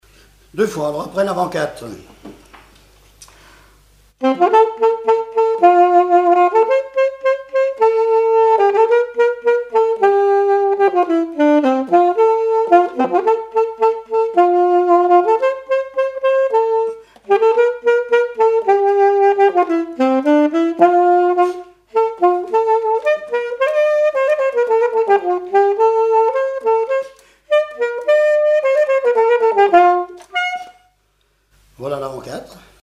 danse : quadrille : avant-quatre
Compagnons d'EthnoDoc - Arexcpo en Vendée
Pièce musicale inédite